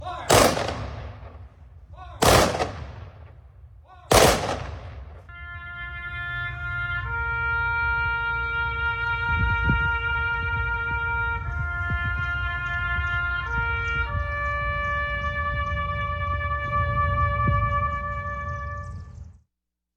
A special 3-volley salute and the playing of TAPS capped off a special Memorial Day service yesterday at the new Washington Commons.